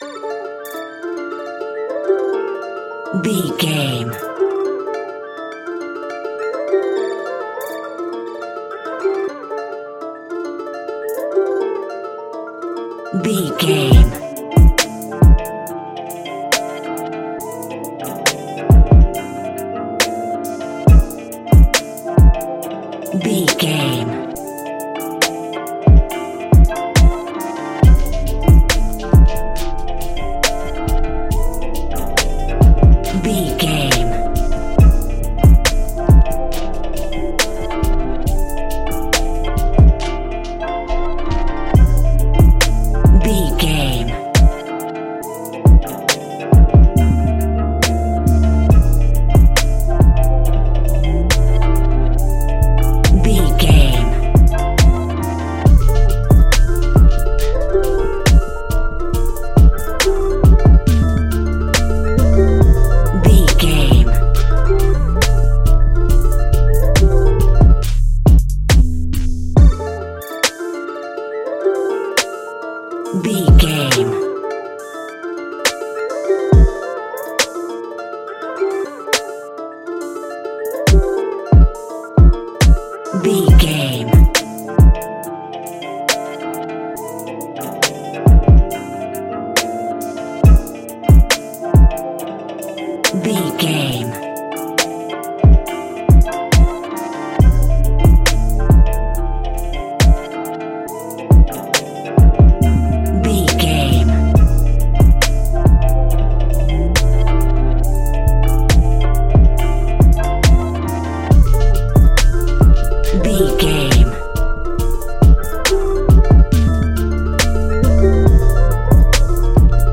Aeolian/Minor
aggressive
intense
bouncy
energetic
dark
drum machine
flute
synthesiser